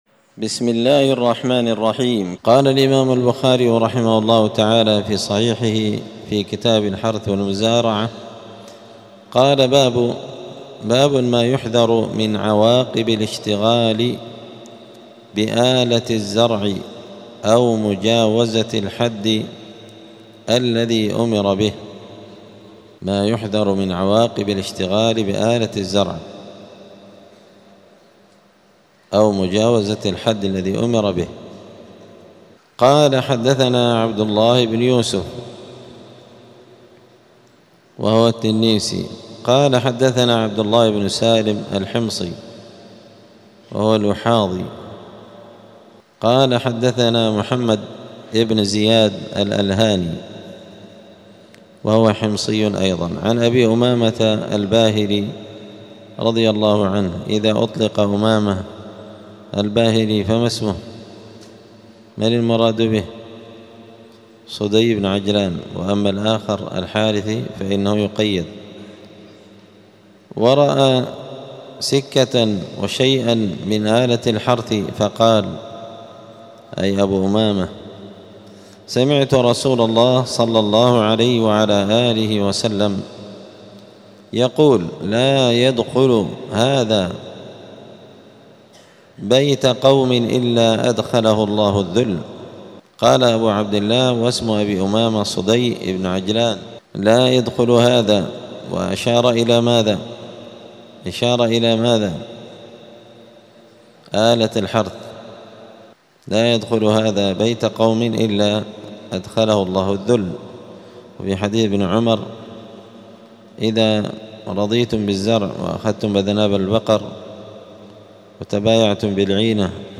دار الحديث السلفية بمسجد الفرقان قشن المهرة اليمن
الأربعاء 23 ربيع الثاني 1447 هــــ | الدروس، دروس الحديث وعلومه، شرح صحيح البخاري، كتاب الحرث والمزارعة من صحيح البخاري | شارك بتعليقك | 11 المشاهدات